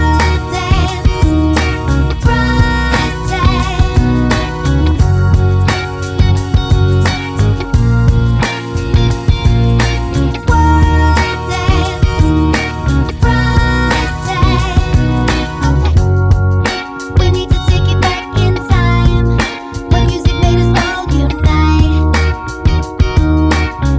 No Rap One Semitone Up Pop (2010s) 3:42 Buy £1.50